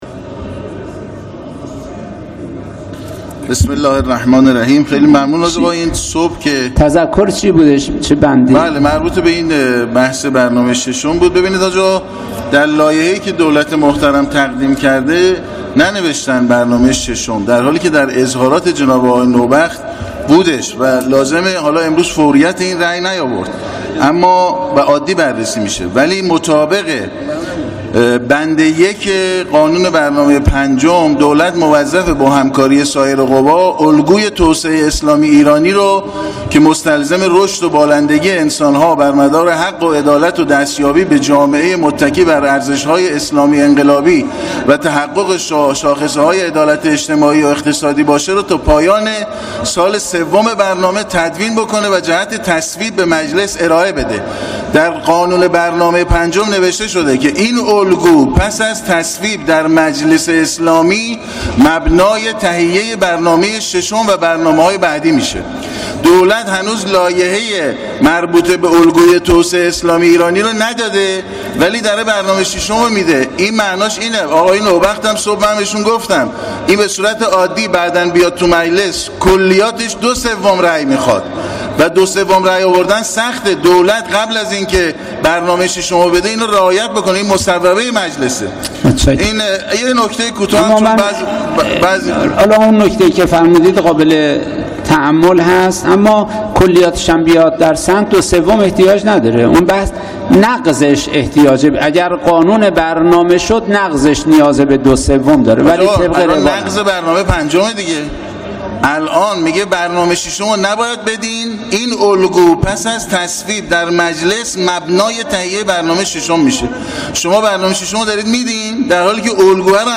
صحن علنی مجلس